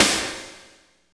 50.05 SNR.wav